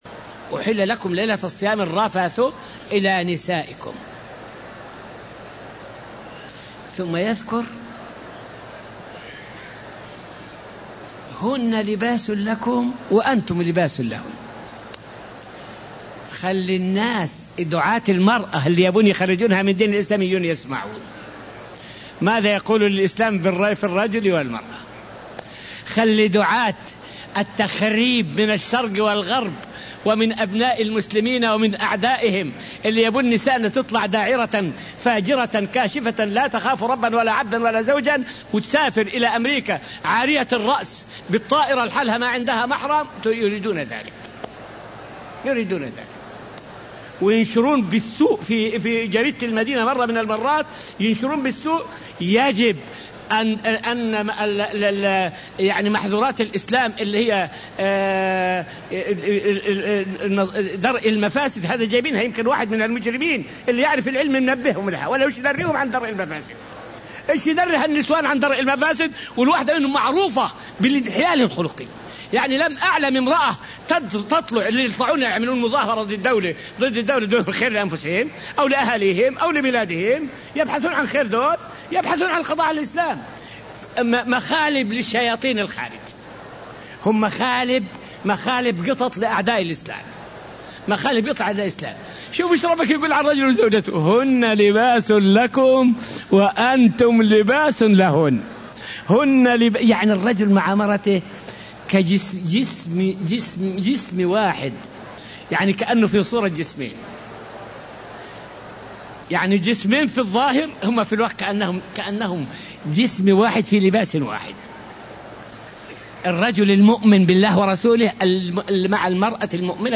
فائدة من الدرس السادس والعشرون من دروس تفسير سورة البقرة والتي ألقيت في المسجد النبوي الشريف حول أنه لا يمكن ترجمة القرآن ترجمة حرفية.